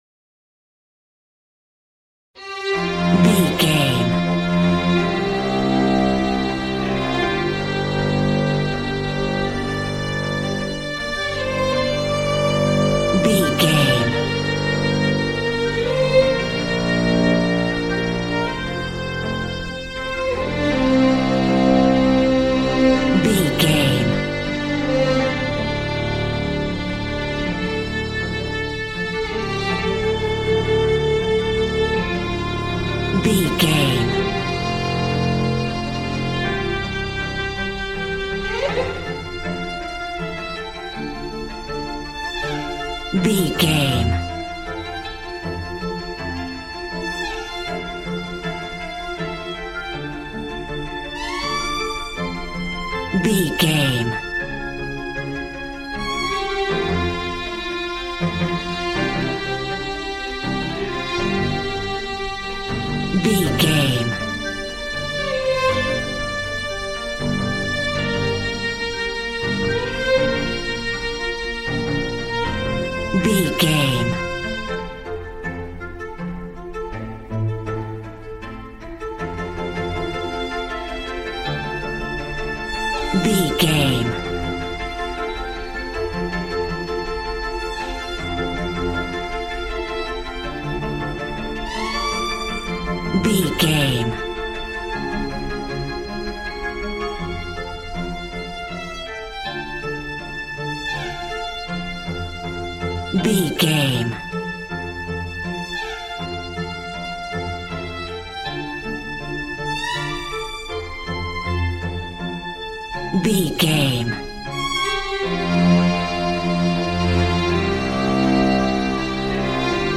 Modern film strings for romantic love themes.
Regal and romantic, a classy piece of classical music.
Ionian/Major
regal
cello
violin
brass